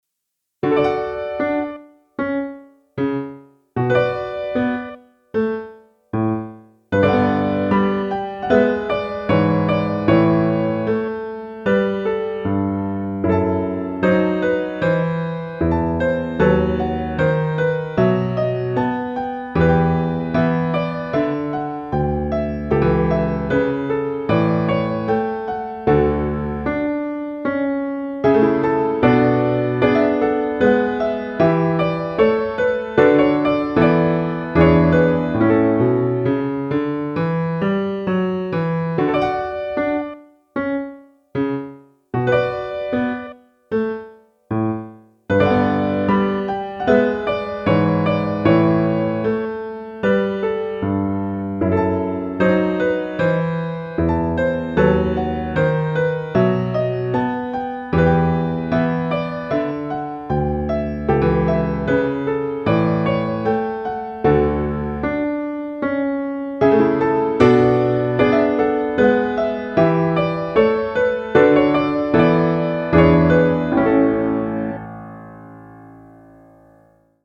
聞く人の感情に訴えるゆっくりした美しい旋律の曲。ピアノでしっとりと演奏してみました。